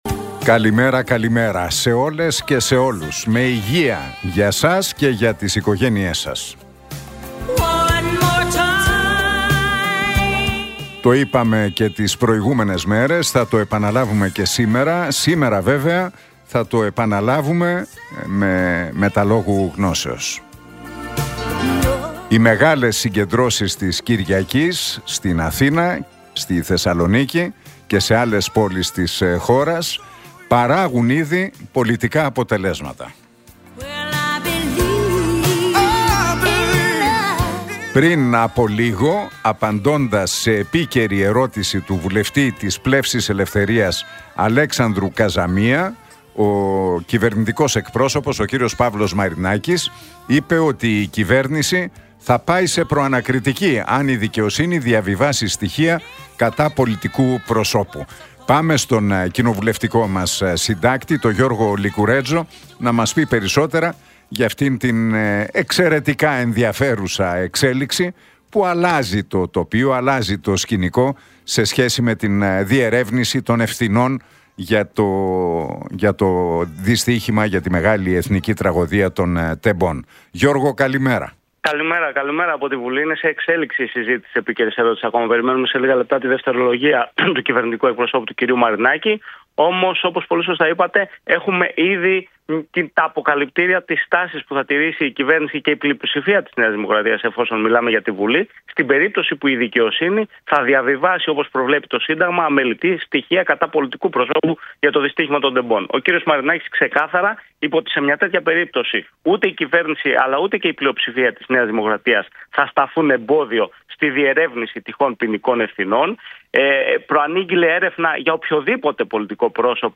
Ακούστε το σχόλιο του Νίκου Χατζηνικολάου στον ραδιοφωνικό σταθμό RealFm 97,8, την Τετάρτη 29 Ιανουαρίου 2025.